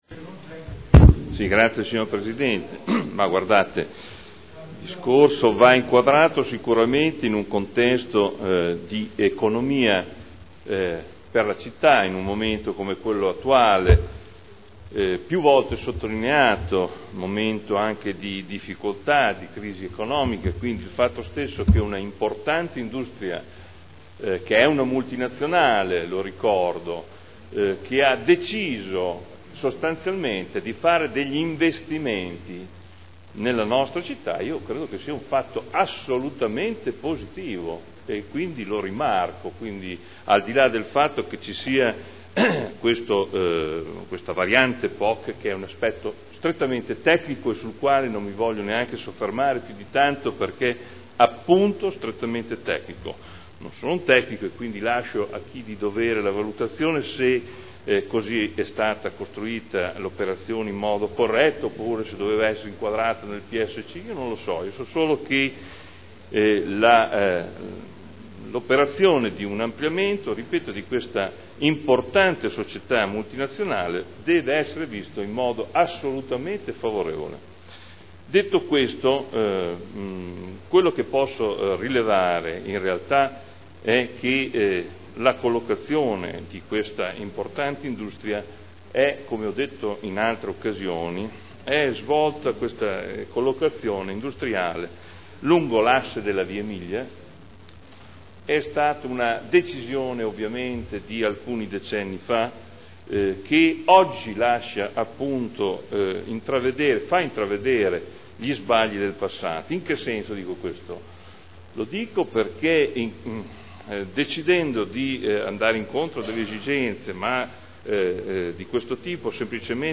Adolfo Morandi — Sito Audio Consiglio Comunale
Seduta del 13/12/2010 Deliberazione: Variante al P.O.C.-RUE – AREA in via Emilia Ovest Z.E. 1481-1502 – Adozione. Dibattito